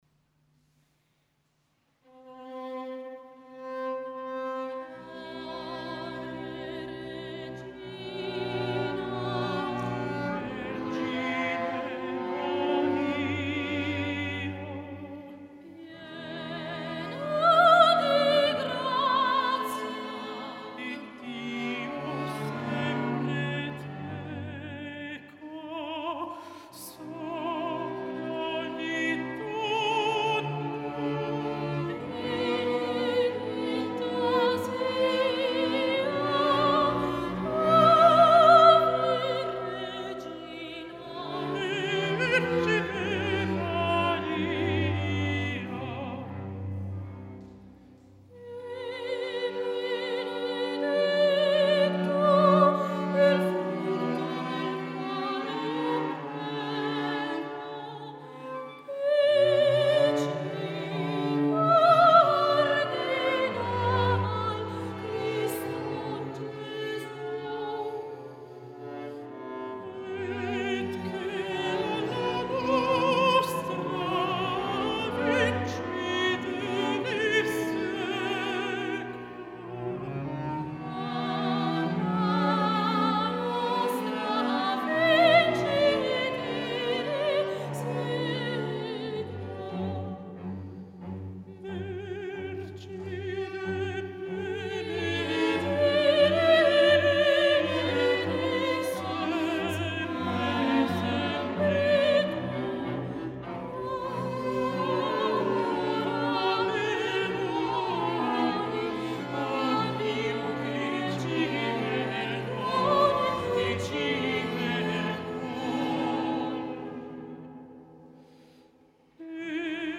Gaetano Donizetti (1797 – 1848) Ave Maria per soprano, contralto ed archi (1845) su testo erroneamente attribuito a Dante Alighieri Manoscritto autografo, Milano, Archivio Storico Ricordi
Soprano
Alto Ensemble Arco Antiqua